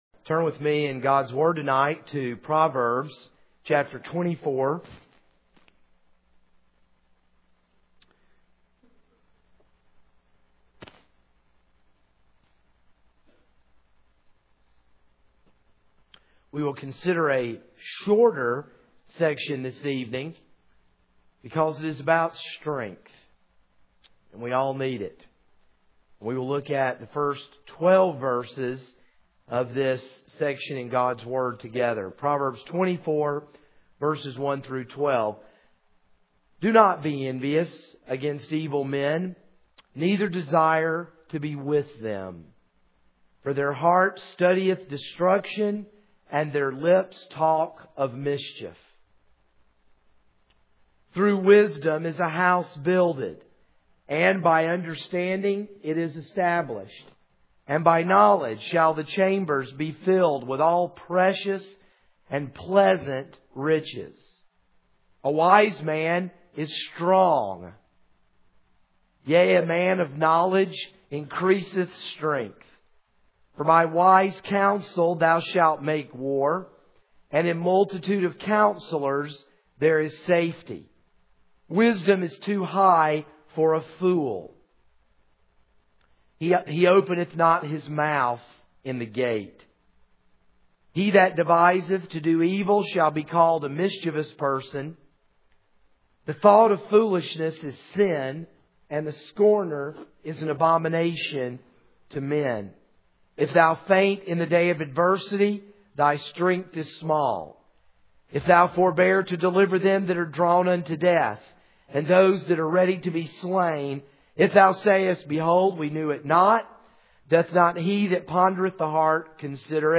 This is a sermon on Proverbs 24:1-12.